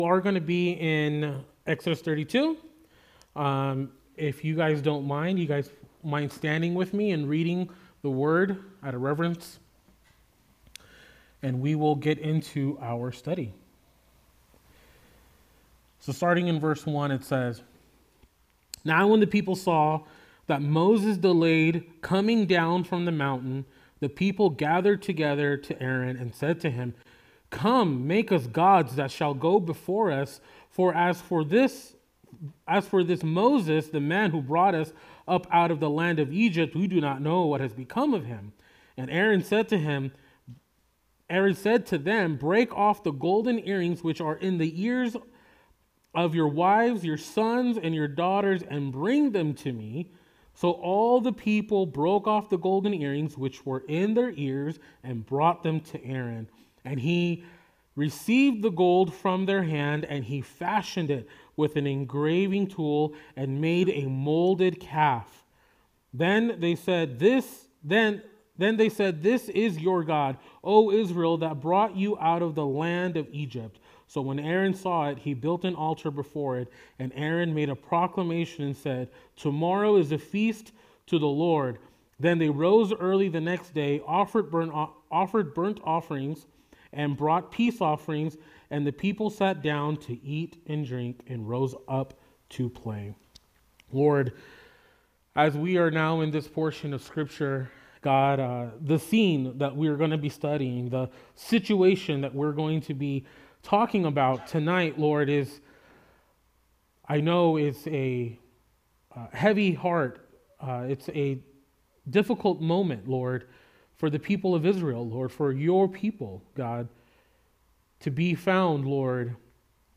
Calvary Chapel Saint George - Sermon Archive
Related Services: Wednesday Nights